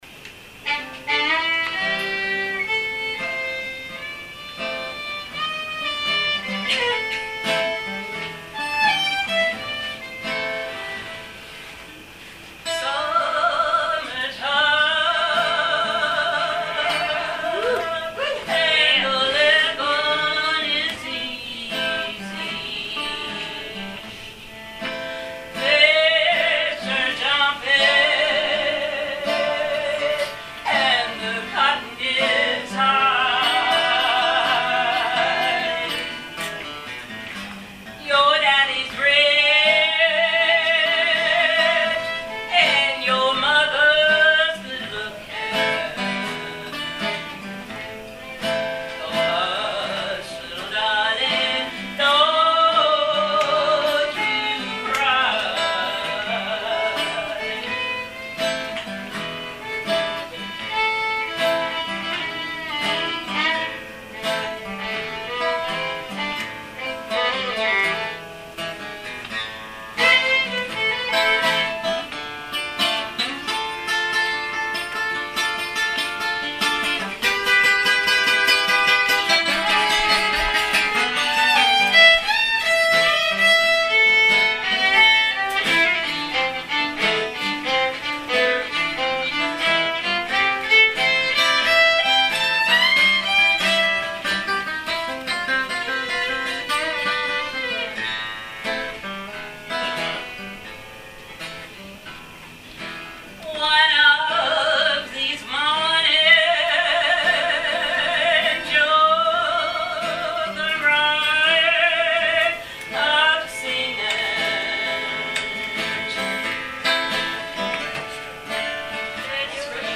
Though more than half the songes were recorded at the campers concert held at the end of the week, some were recorded at informal late night singing sessions.